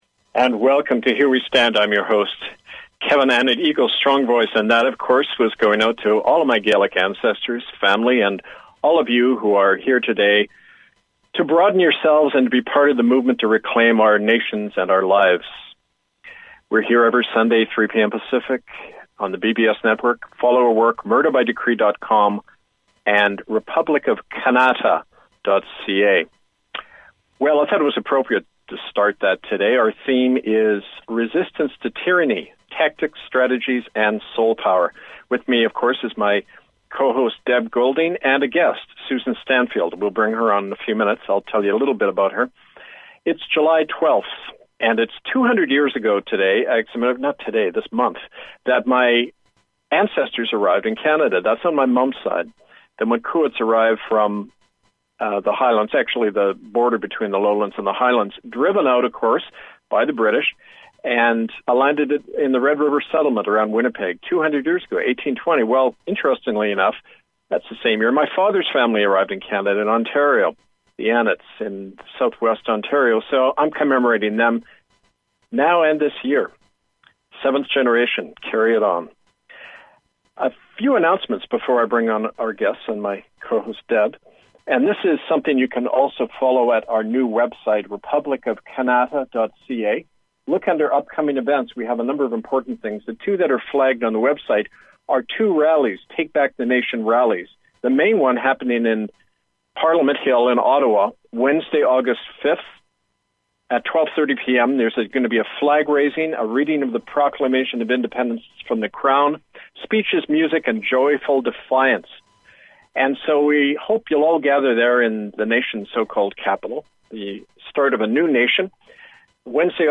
Talk Show Episode
Scottish bagpipes